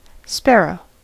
Ääntäminen
IPA : /ˈspæɹəʊ/ IPA : /ˈspɛɹoʊ/